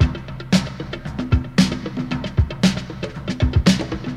• 115 Bpm Drum Loop Sample D Key.wav
Free drum loop sample - kick tuned to the D note.
115-bpm-drum-loop-sample-d-key-7Mm.wav